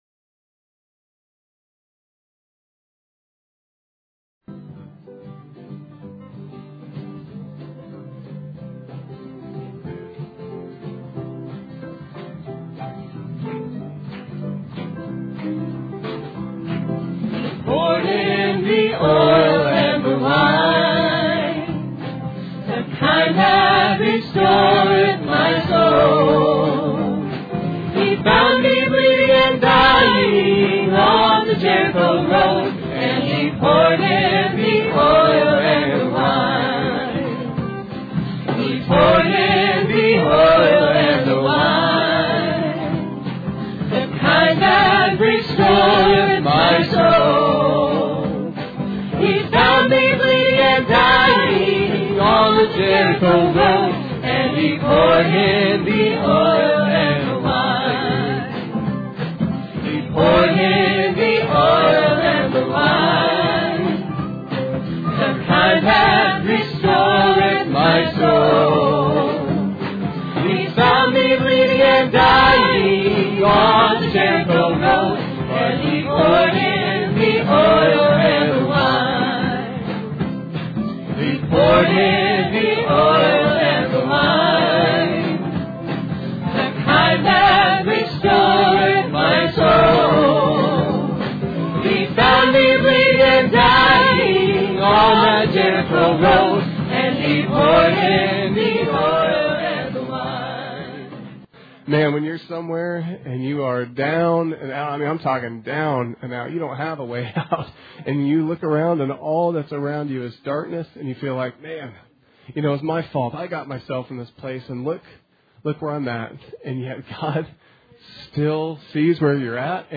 Sermon 11/6/16 – RR Archives